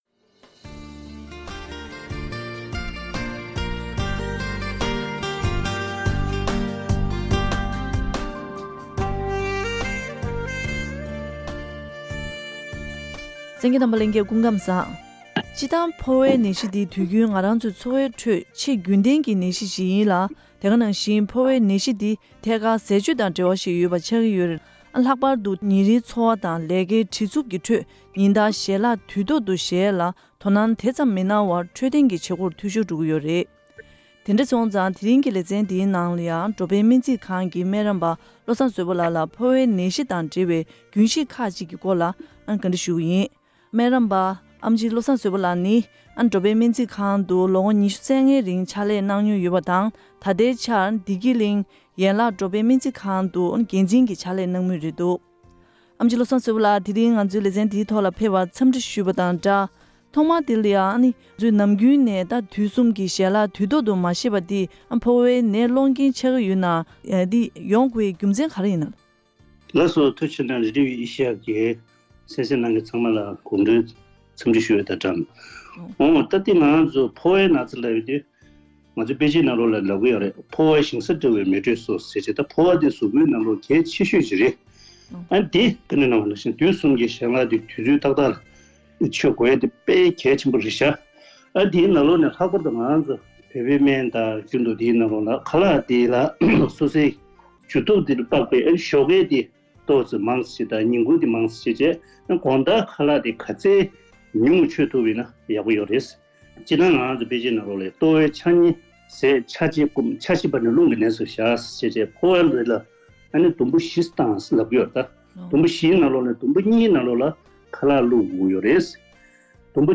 སྤྱིར་བཏང་ཕོ་བའི་ནད་གཞི་ནི་ཆེས་རྒྱུན་ལྡན་གྱི་ནད་གཞི་ཞིག་ཡིན་ཞིང་། དེ་ཡང་ཐད་ཀར་ཟས་སྤྱོད་དང་འབྲེལ་བ་ཡོད་པ་ཞིག་ཡིན་པའི་ཐོག ཉིན་རེའི་འཚོ་བ་དང་ལས་ཀའི་བྲེལ་འཚུབ་ཀྱི་ཁྲོད། ཉིན་ལྟར་ཞལ་ལག་དུས་ཐོག་ཏུ་བཞེས་པར་ཐུགས་གནང་དེ་ཙམ་མི་གནང་བར་འཕྲོད་བསྟེན་གྱི་བྱེད་སྒོར་མཐུད་ཤོར་འགྲོ་གི་ཡོད་པ་རེད། ཐེངས་འདིའི་བཅར་འདྲིའི་ལེ་ཚན